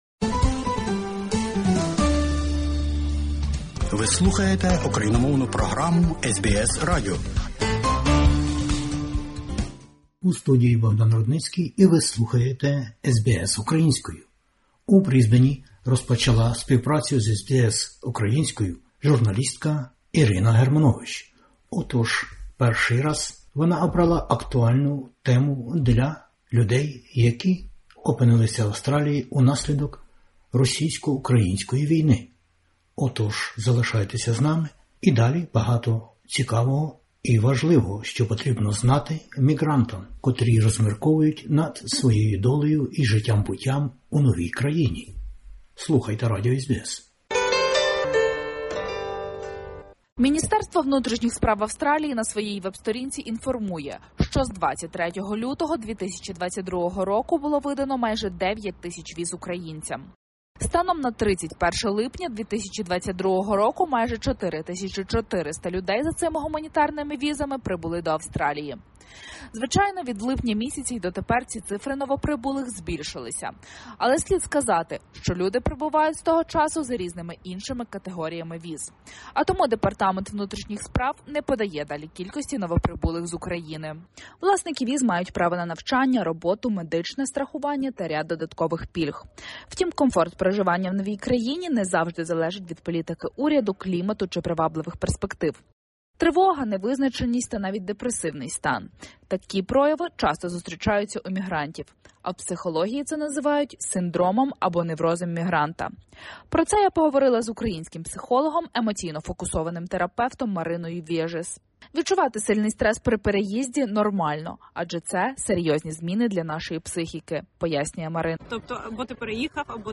розмовляє з українським нейропсихологом, емоційно-фокусованим терапевтом